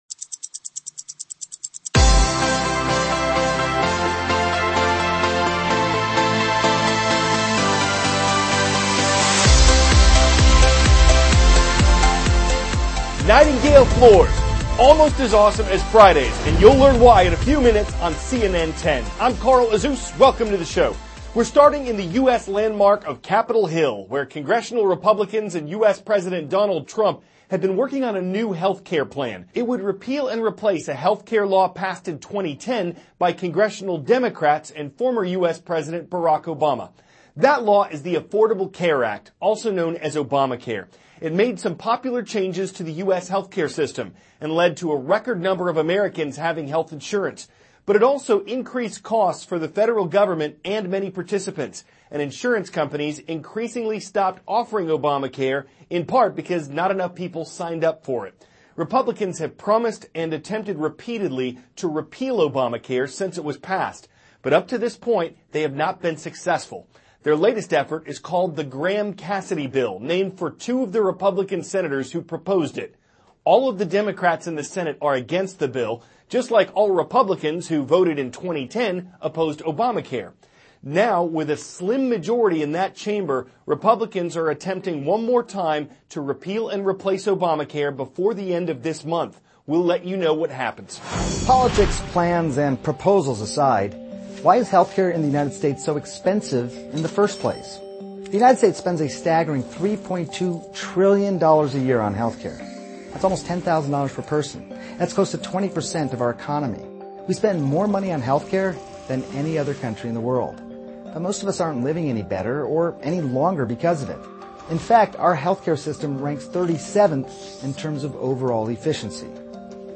CARL AZUZ, cnn 10 ANCHOR: Nightingale floors, almost as awesome as Fridays.